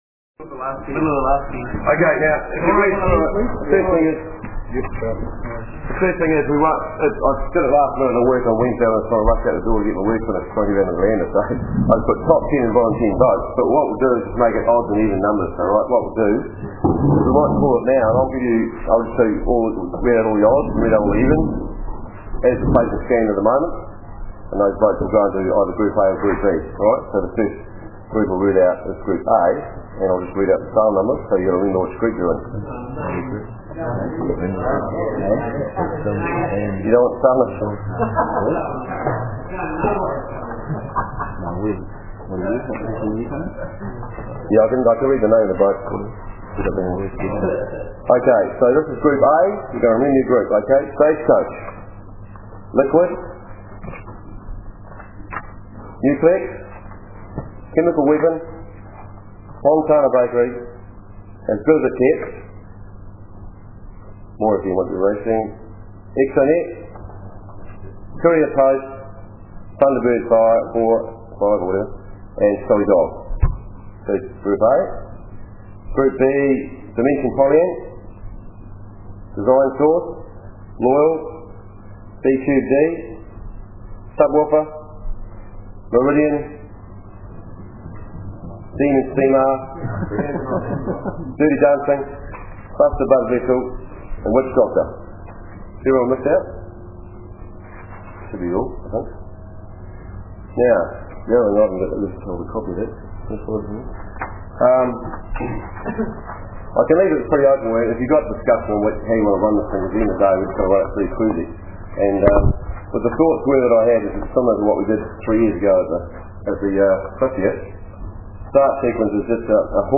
Super Cup Sprint Series Briefing
sprint_series_briefing.mp3